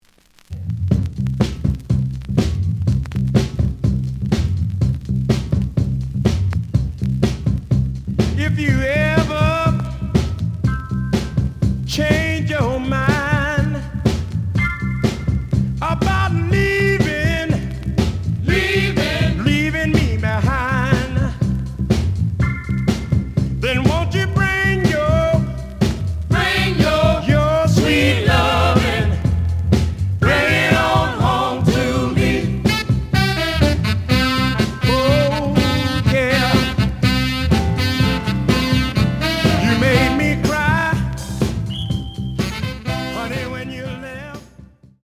The audio sample is recorded from the actual item.
●Genre: Soul, 60's Soul
Slight damage on both side labels. Plays good.)